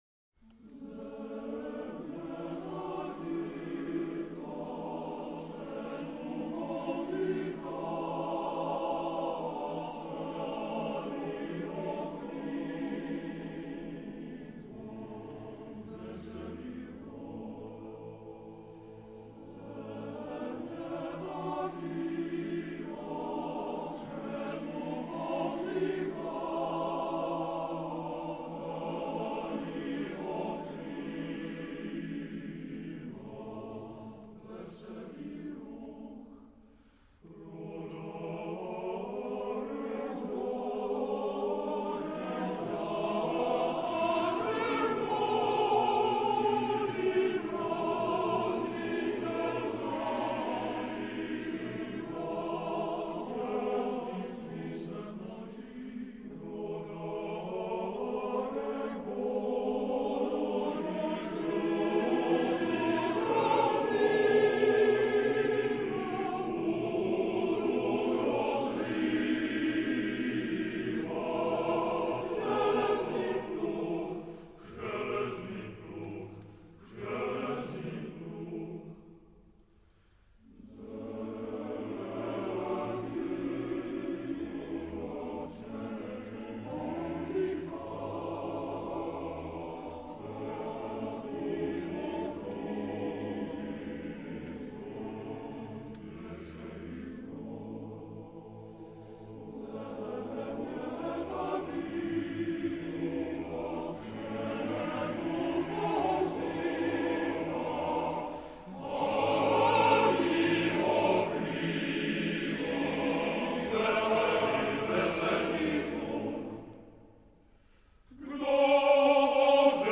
FF:VH_15b Collegium musicum - mužský sbor